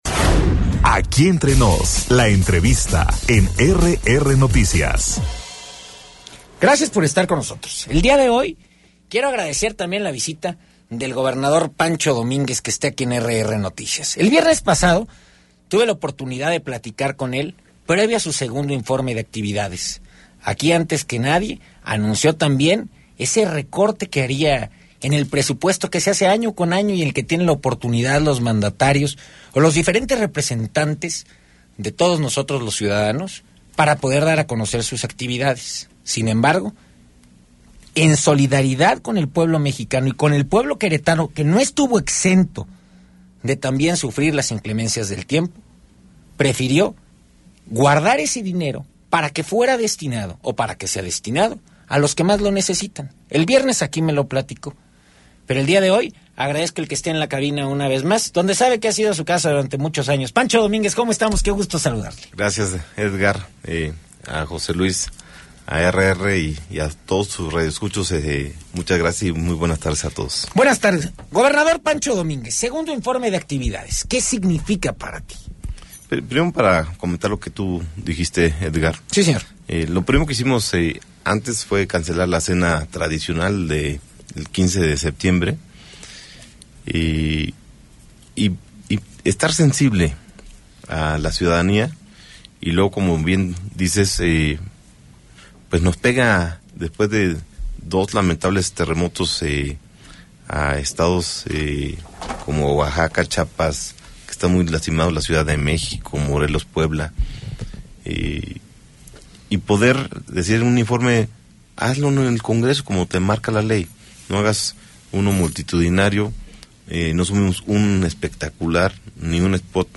Entrevista exclusiva con el Gobernador Pancho Domíngez en RRNoticias - RR Noticias